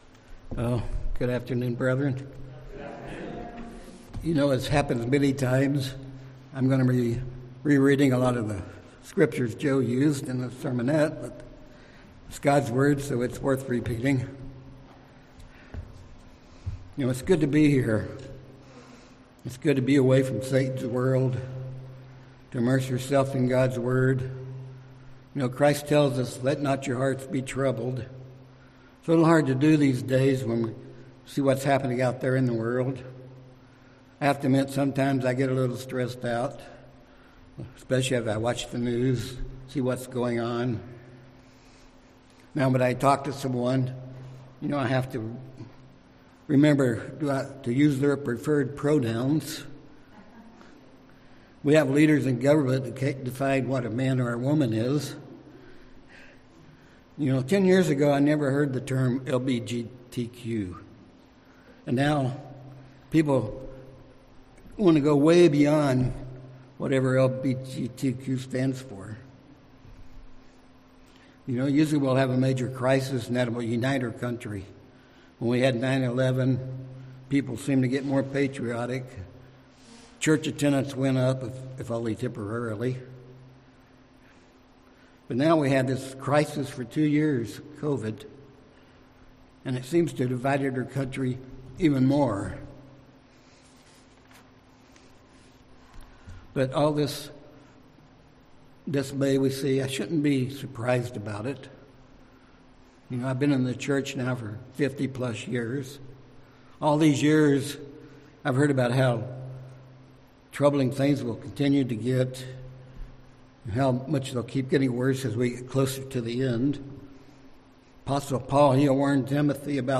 This sermon gives points on how we can endure to the end. Realize we are in a race we must win.